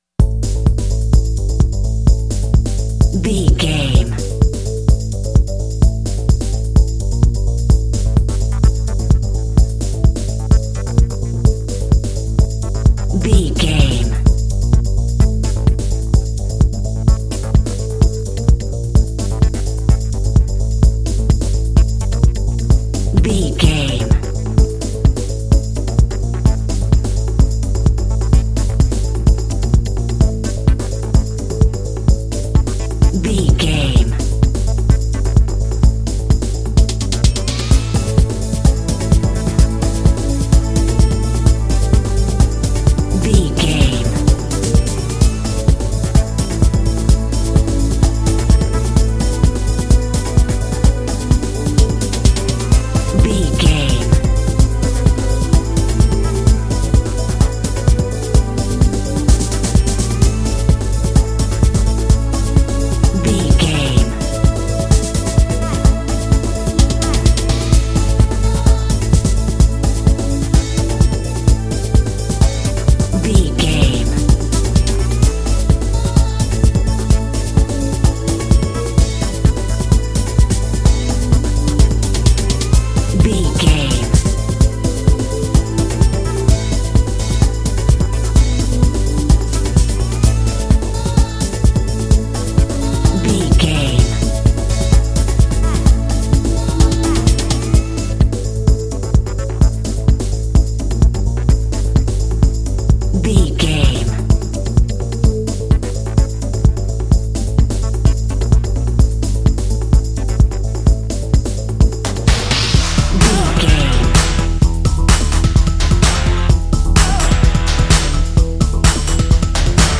Epic / Action
Aeolian/Minor
Fast
drum machine
synthesiser
strings
Eurodance